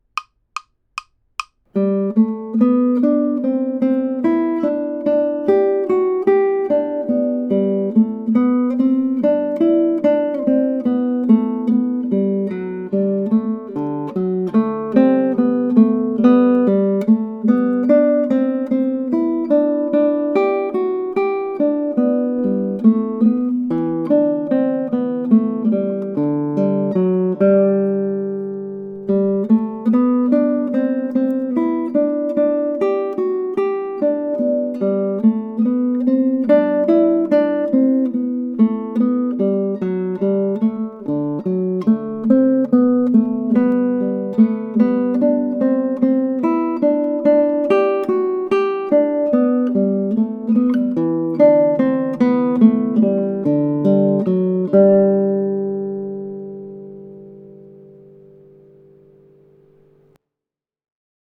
Jesu, Joy of Man's Desiring sounds best performed at a brisk allegro tempo (120 BPM+).
This version is slower than the video above, making it ideal for playing along with.
Jesu, Joy of Man's Desiring | Melody only—play along!
Jesu_joy_man_GTR_melody.mp3